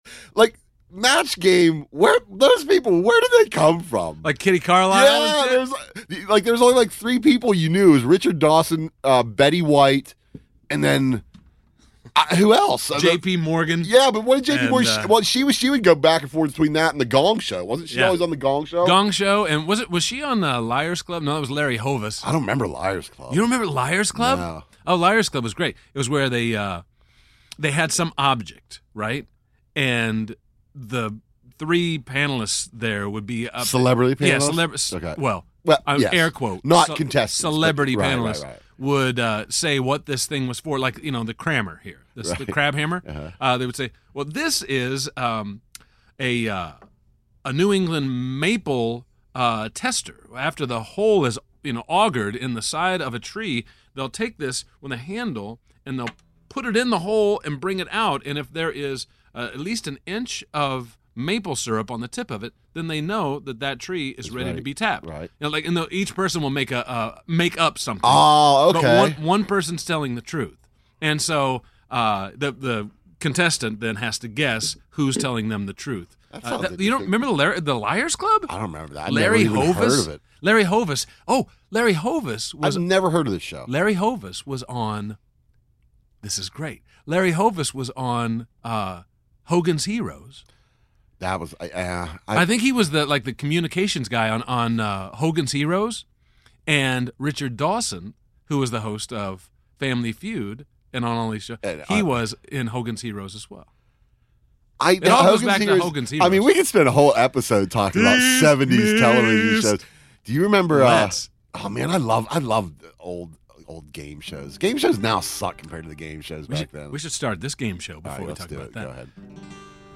Plus your YAF calls.